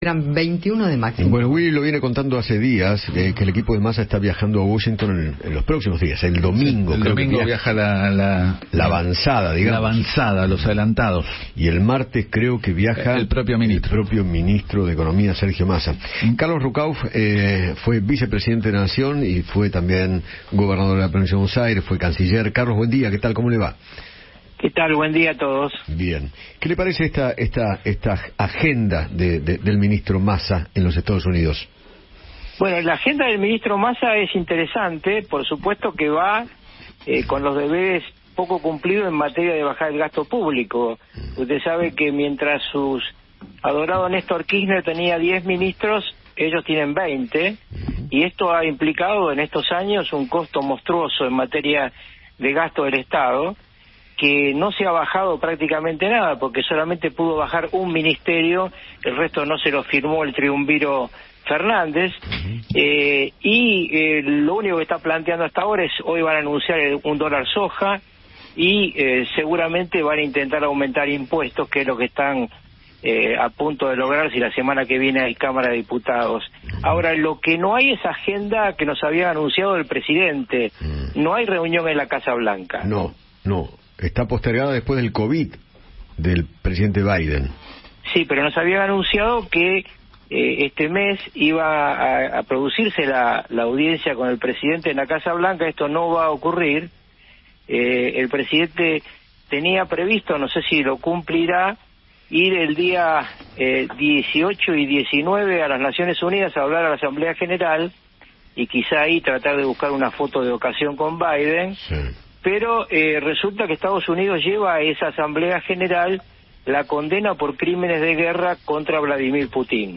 Carlos Ruckauf, ex vicepresidente de la Nación, habló con Eduardo Feinmann sobre el viaje de Sergio Massa a Estados Unidos y se refirió a las reuniones que mantendrá el ministro de Economía con el FMI.